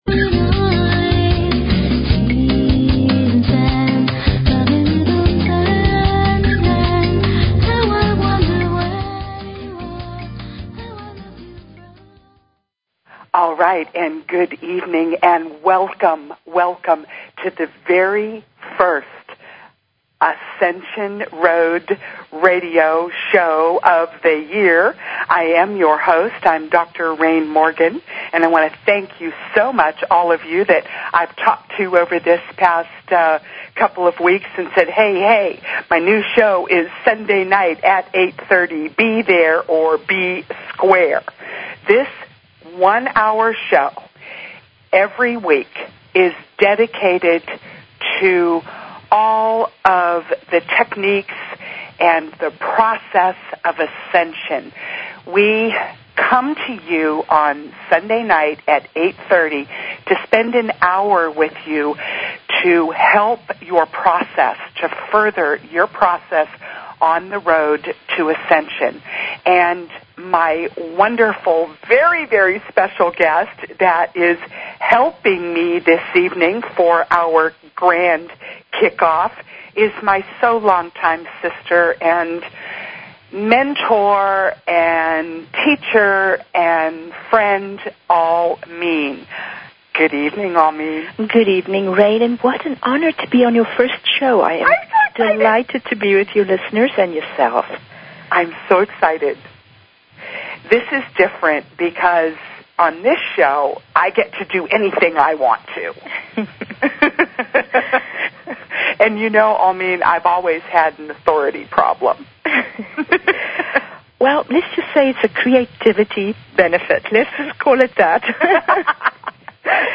Talk Show Episode, Audio Podcast, Ascension_Road and Courtesy of BBS Radio on , show guests , about , categorized as